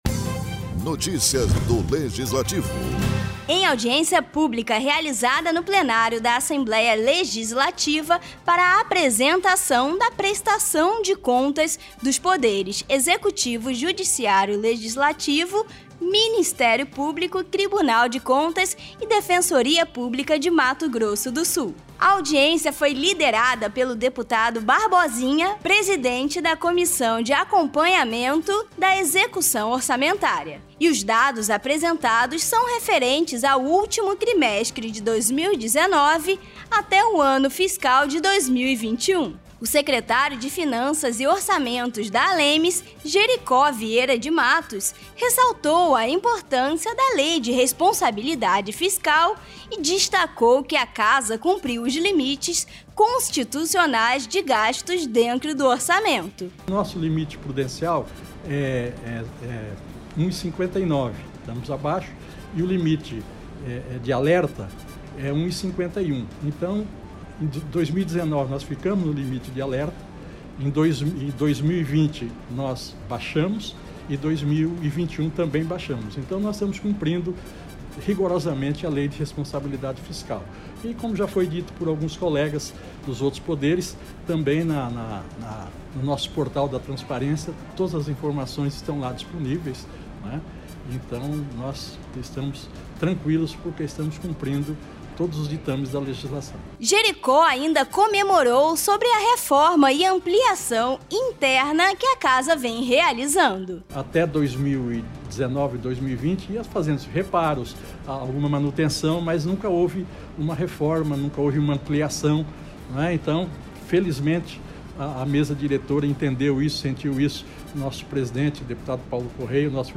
Em Audiência Pública realizada no plenário da Assembleia Legislativa de forma híbrida, foi apresentado prestação de contas dos poderes - Executivo, Judiciário, Legislativo, Ministério Público, Tribunal de Contas e Defensoria Pública de Mato Grosso do Sul.